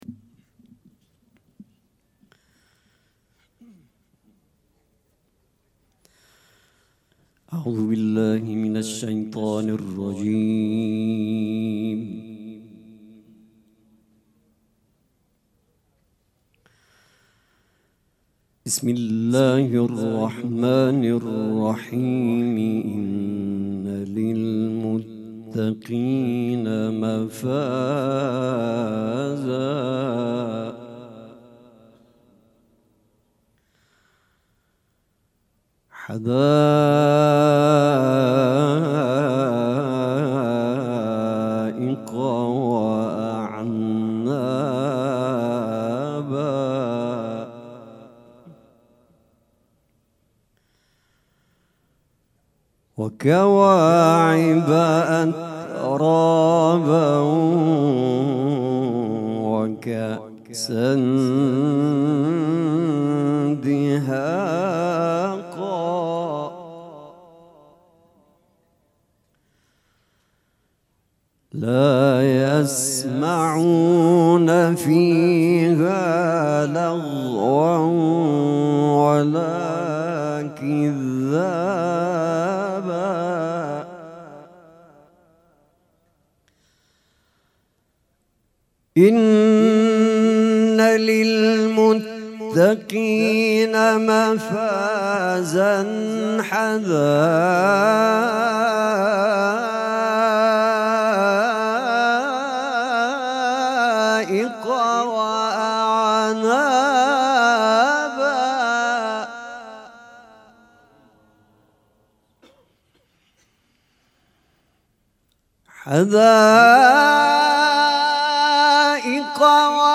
مراسم عزاداری شام شهادت حضرت رقیه سلام الله علیها
قرائت قرآن